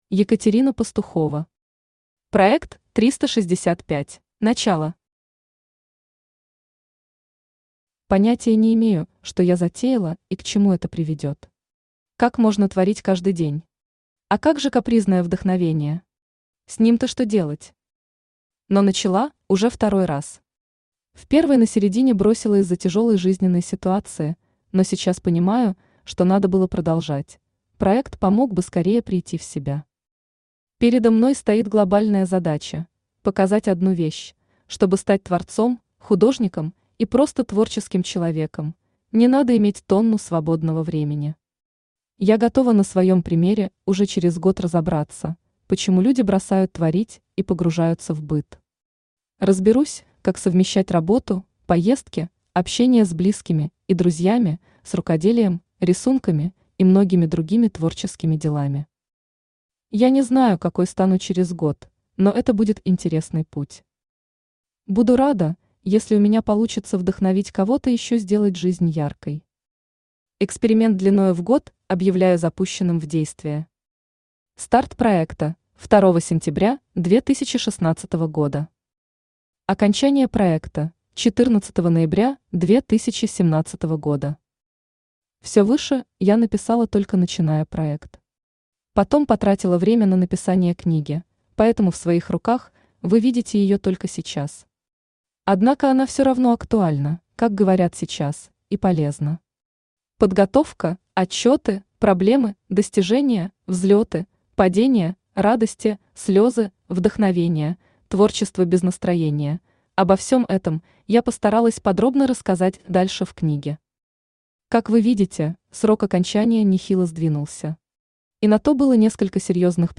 Aудиокнига Проект 365 Автор Екатерина Евгеньевна Пастухова Читает аудиокнигу Авточтец ЛитРес.